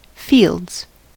fields: Wikimedia Commons US English Pronunciations
En-us-fields.WAV